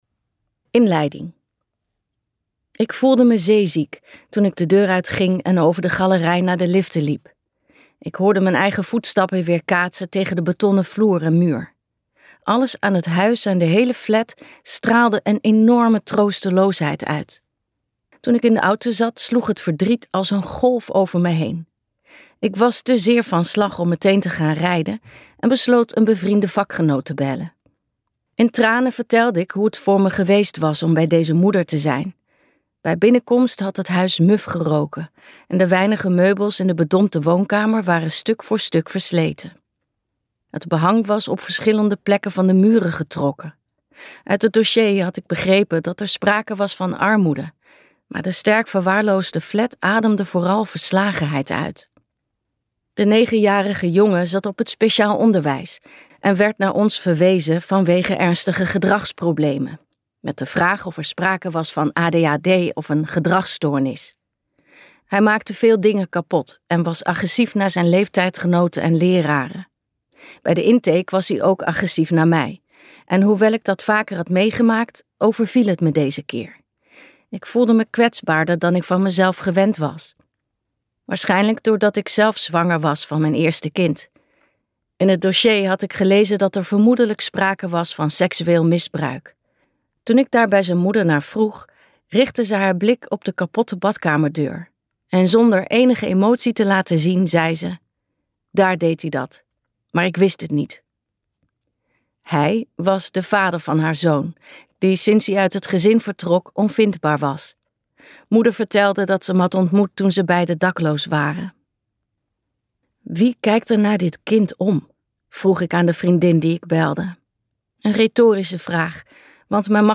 Uitgeverij Ten Have | Iedereen kan het verschil maken luisterboek